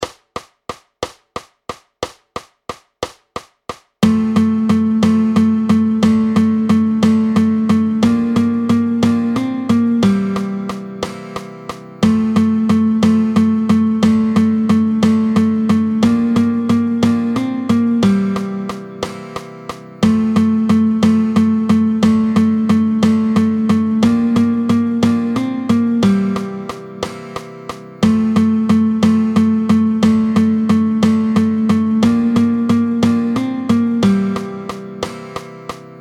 tempo 90, ternaire